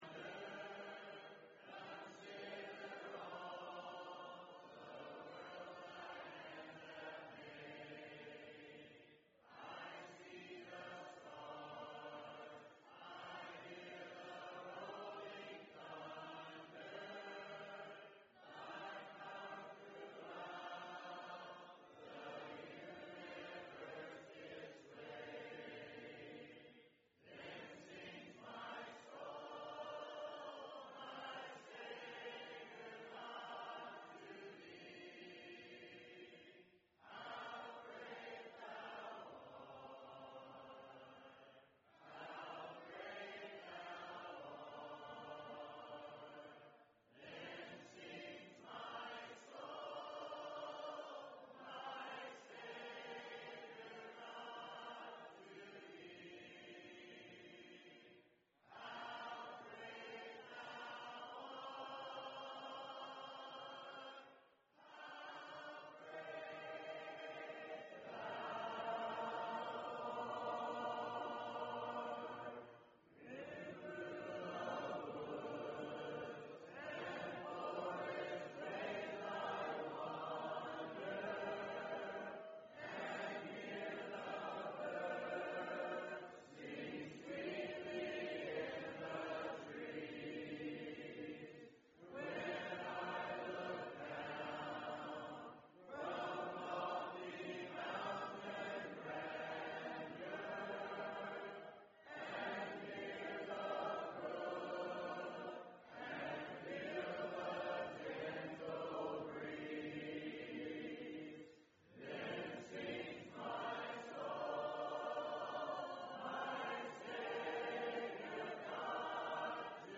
Event: 4th Annual Men's Development Conference